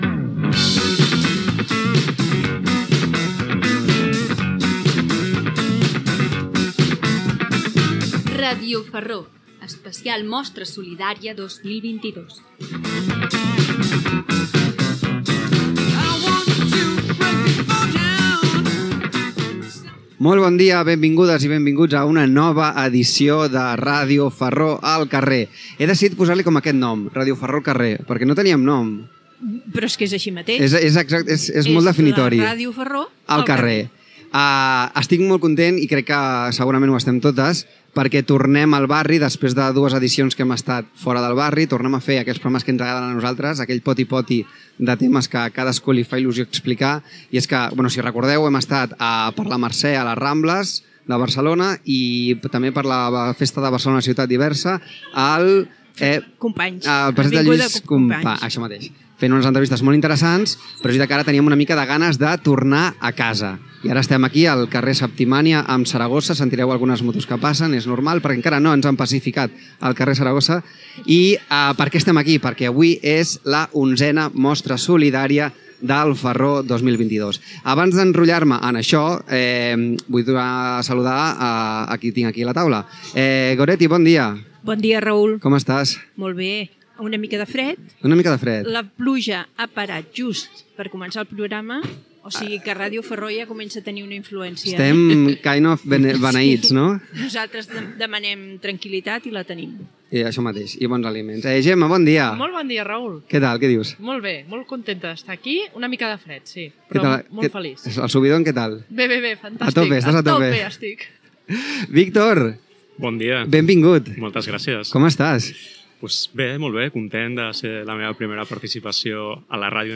Programa fet des de l’11ena Mostra Solidària del Farró 2022, al carrer, enmig de la fira. Presentació dels invitats i descripció de coses que es fan a la mostra i a l'associació de veïns.
Informatiu